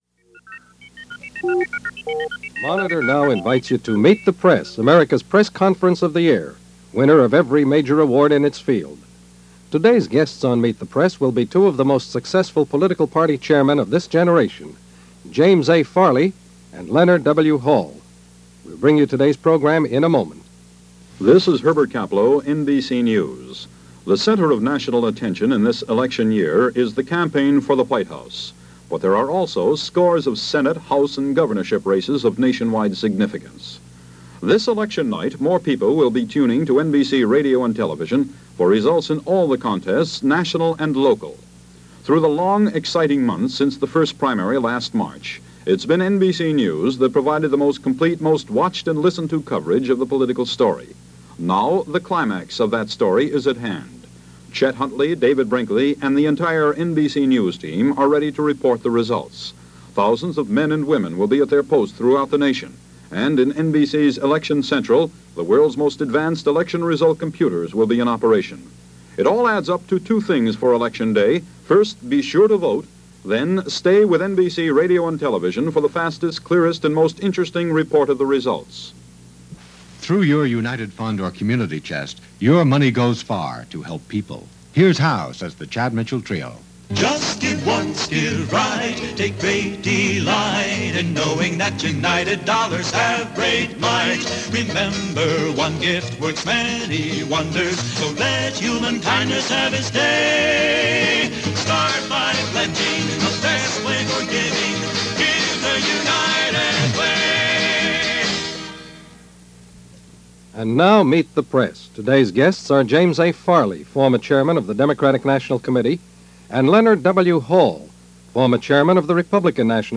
An interview with key players in the GOP and Democratic Partys.